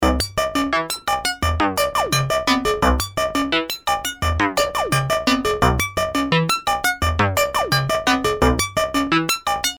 Пинг с пульса. У него идеальная форма для бонгов.
Первые звуки после сборки.